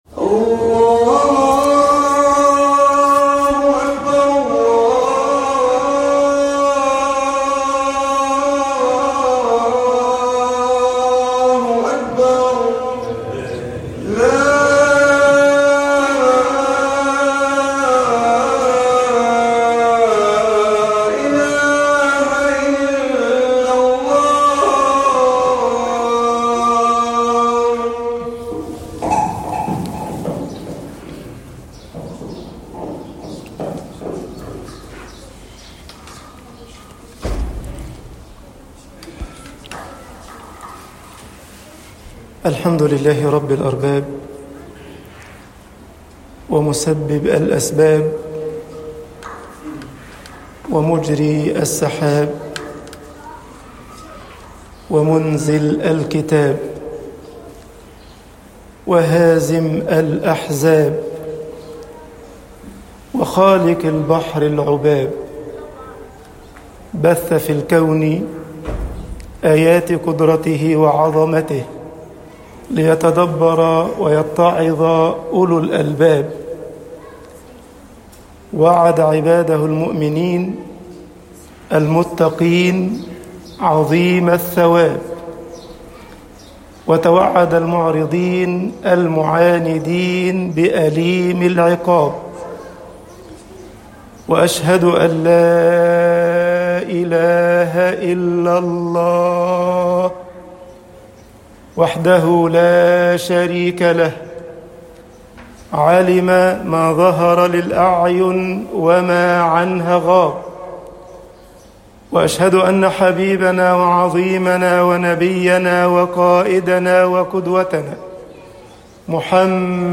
خطب الجمعة - مصر الإبتلاء رحمة لا نقمة طباعة البريد الإلكتروني التفاصيل كتب بواسطة